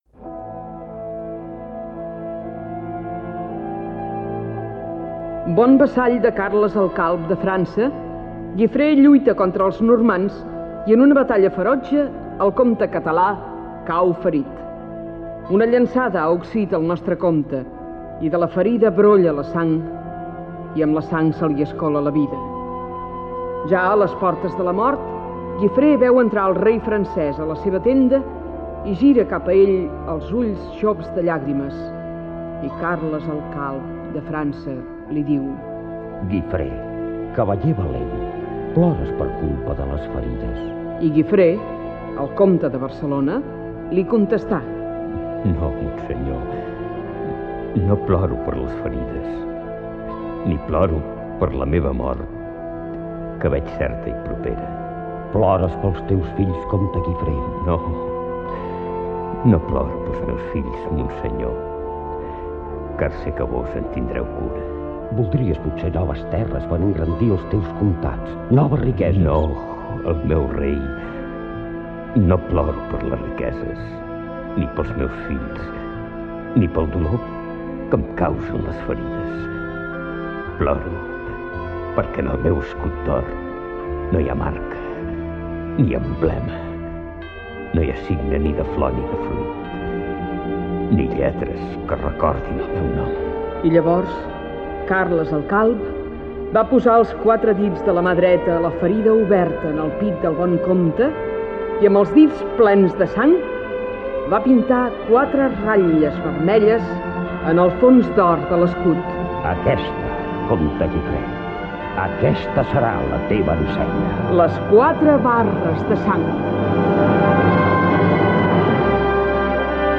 El comte Guifré i les quatre barres, sintonia del programa